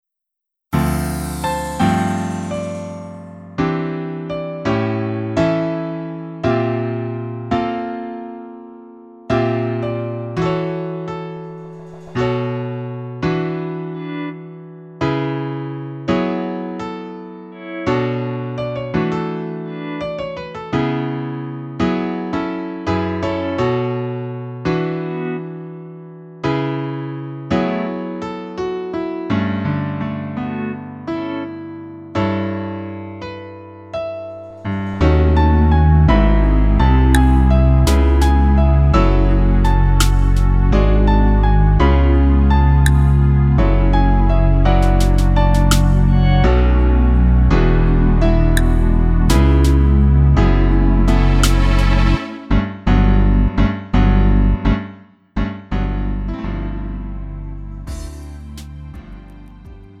음정 -1키 3:57
장르 가요 구분 Lite MR
Lite MR은 저렴한 가격에 간단한 연습이나 취미용으로 활용할 수 있는 가벼운 반주입니다.